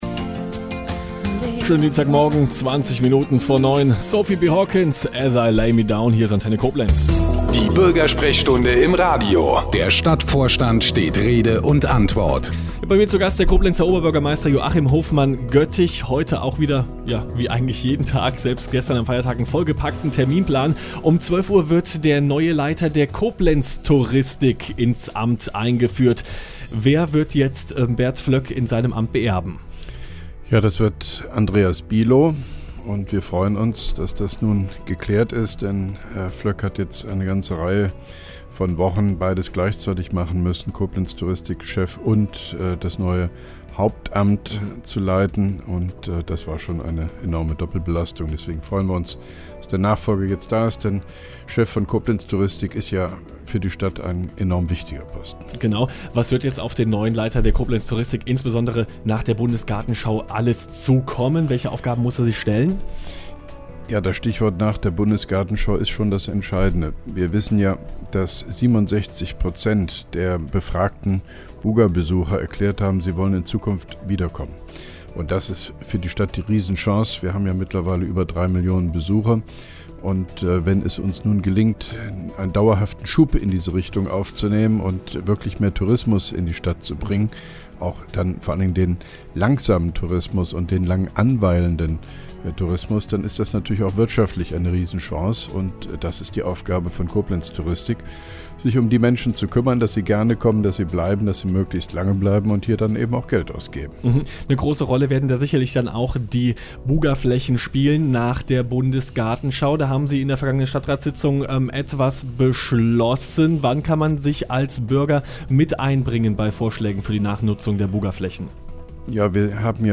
(2) Koblenzer Radio-Bürgersprechstunde mit OB Hofmann-Göttig 04.10.2011